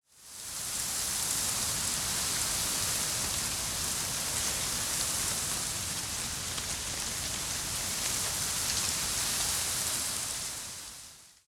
328d67128d Divergent / mods / Soundscape Overhaul / gamedata / sounds / ambient / soundscape / wind / windtree_10.ogg 293 KiB (Stored with Git LFS) Raw History
windtree_10.ogg